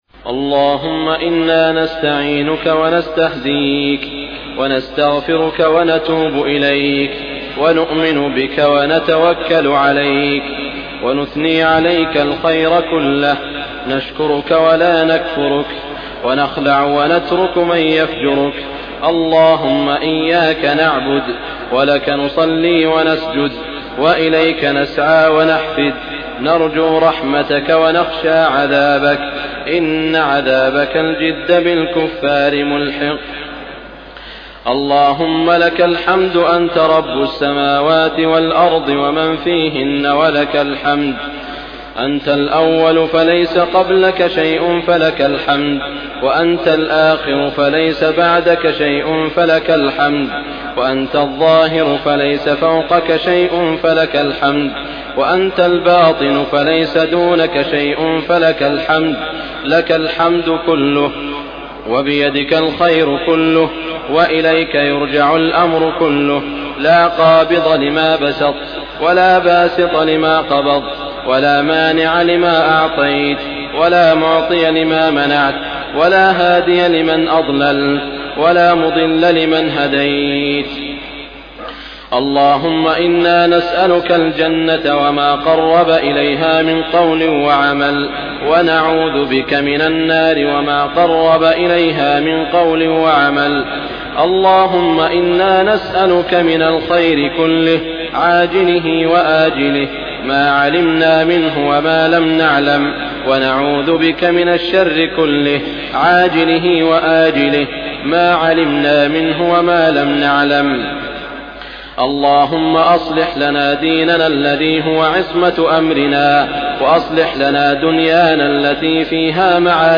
دعاء القنوت رمضان 1413هـ | Dua Ramadan 1413H > تراويح الحرم المكي عام 1413 🕋 > التراويح - تلاوات الحرمين